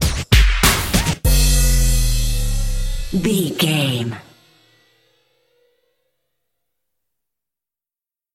Ionian/Major
drum machine
synthesiser
hip hop
Funk
neo soul
acid jazz
energetic
bouncy
Triumphant
funky